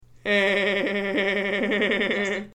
laughing